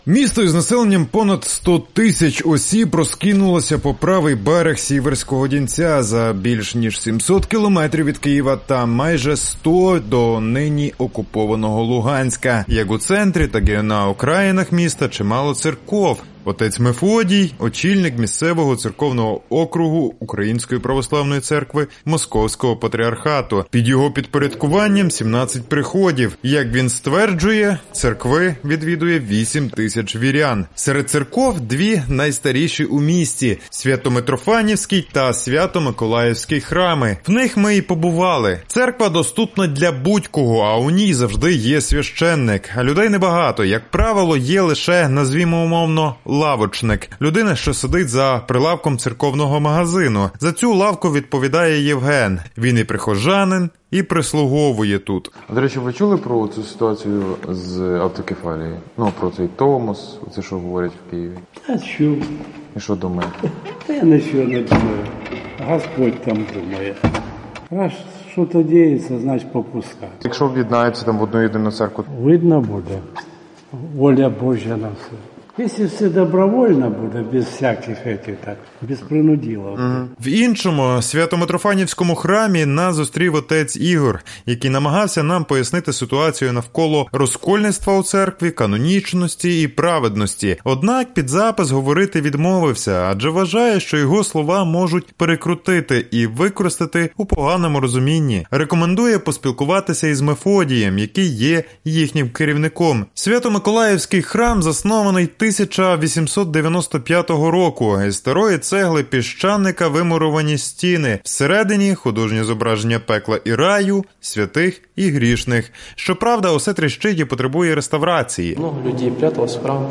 Лисичанська церква проти томосу? – репортаж із релігійної громади на Донбасі